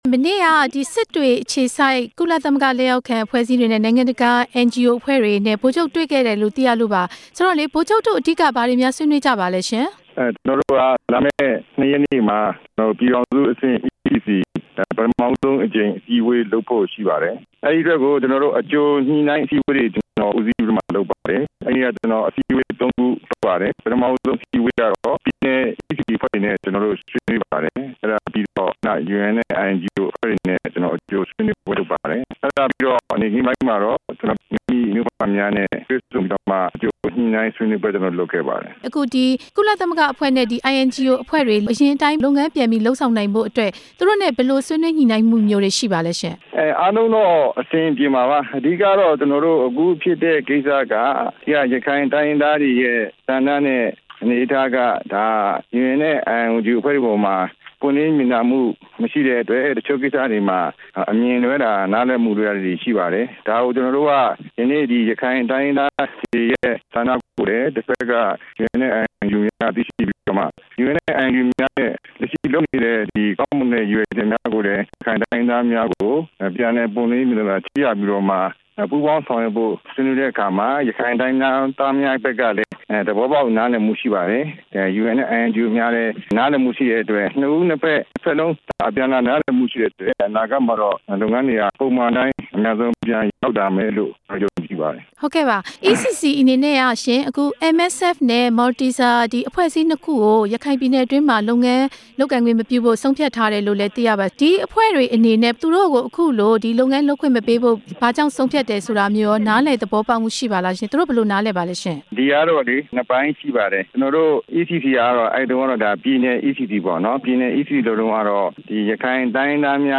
ဗိုလ်ချုပ် မောင်မောင်အုန်းနဲ့ မေးမြန်းချက်